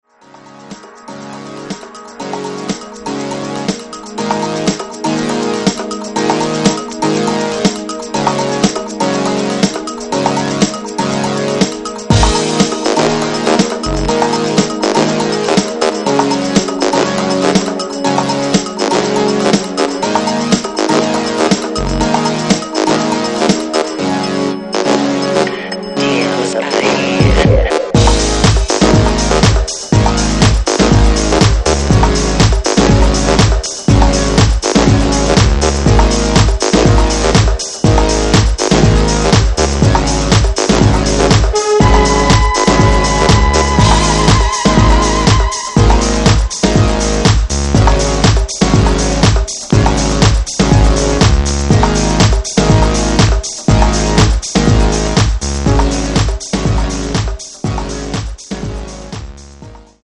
A slice of quality techno